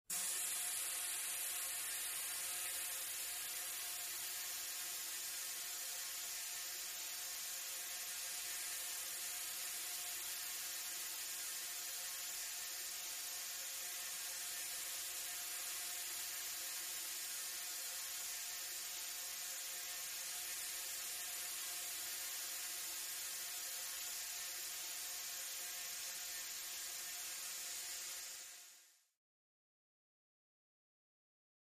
Light Air Hiss Through Pipe